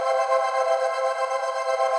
SaS_MovingPad05_120-C.wav